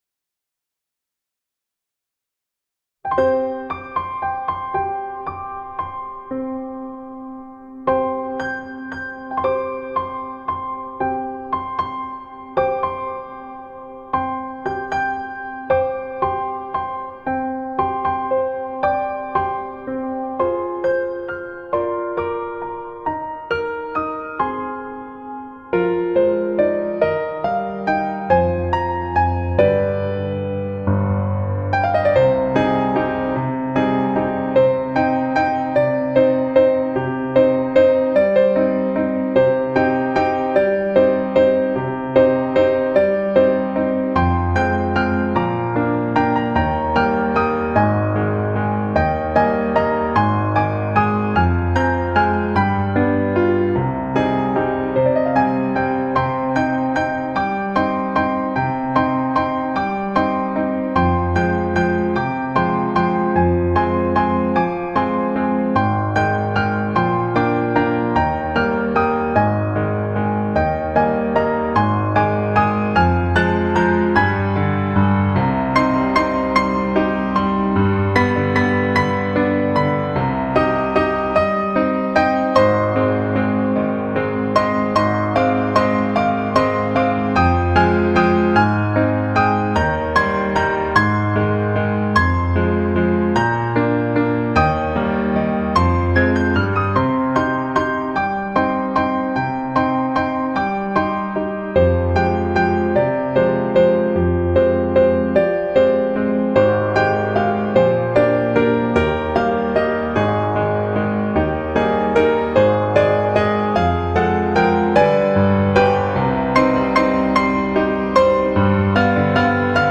piano cover / tutorial
(HAUNTING VERSION)